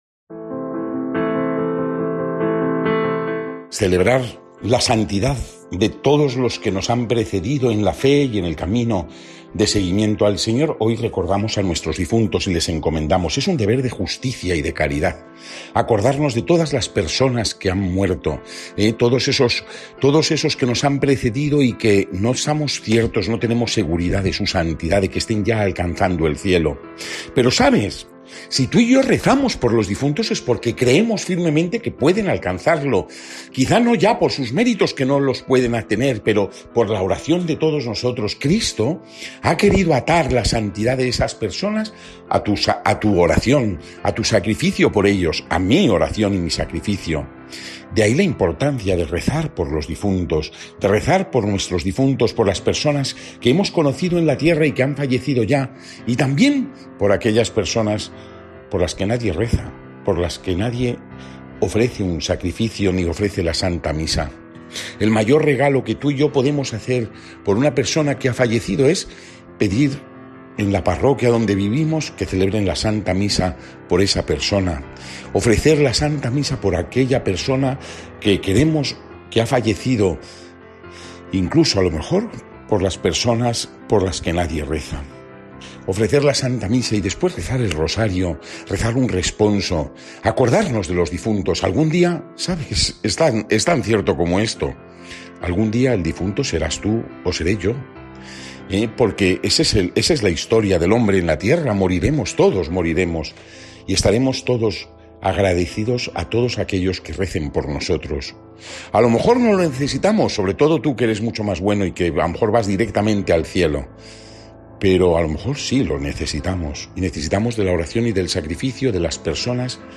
Evangelio según san Juan (11, 17-27) y comentario